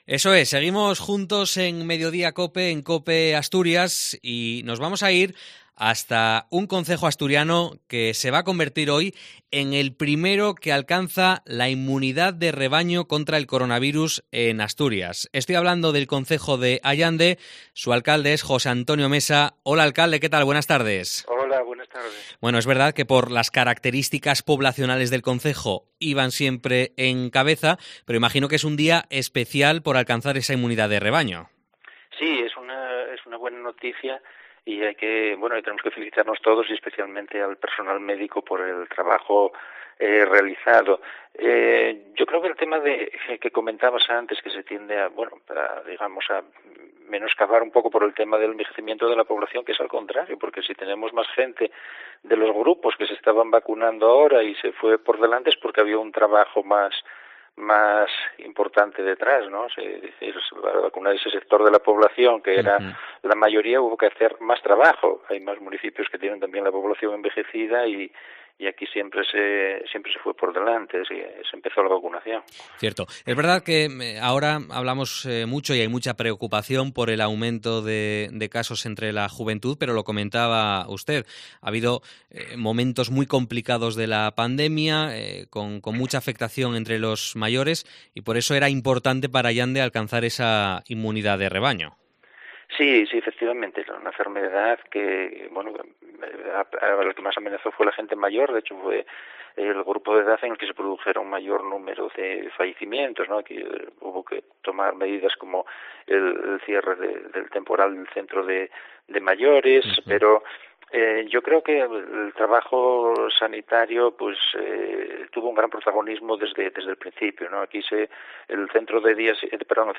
Entrevista al alcalde de Allande, José Antonio Mesa